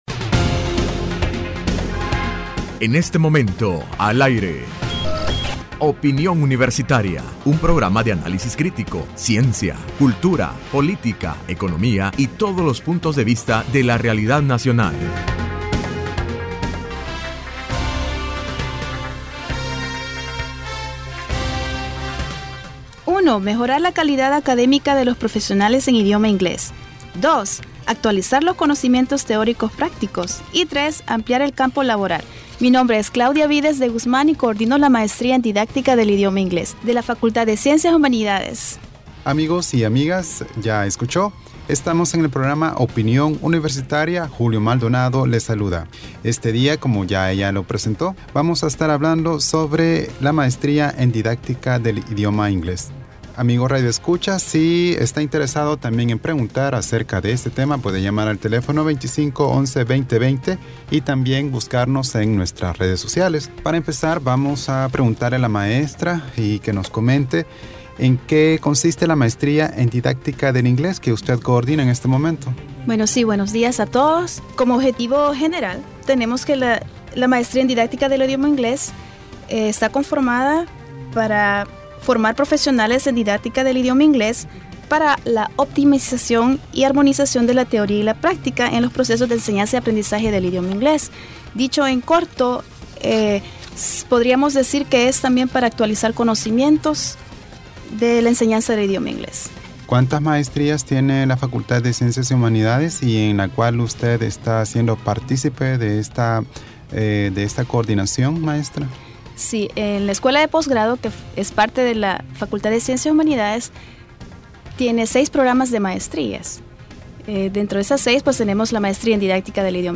Entrevista Opinión Universitaria(29 de septiembre 2015): Maestría en didáctica del Idioma Ingles que realiza la Escuela de Post grado de la facultad de Ciencias y Humanidades.